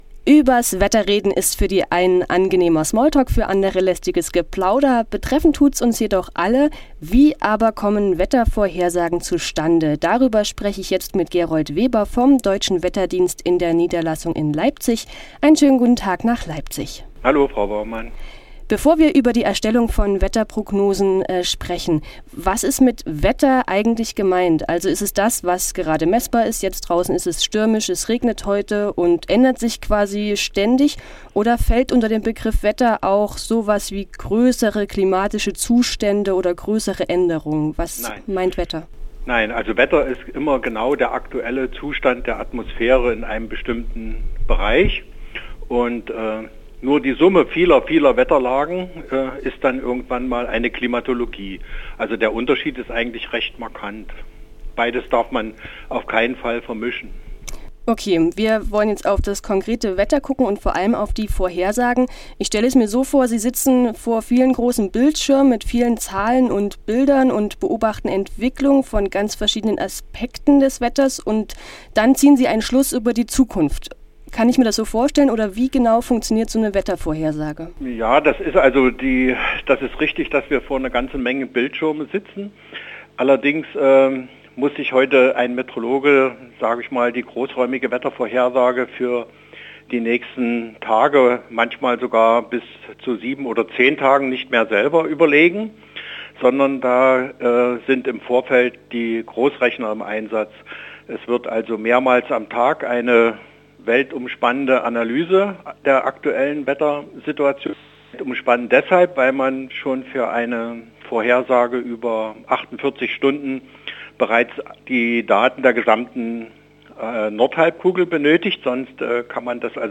Wer wie was Wetter | Interview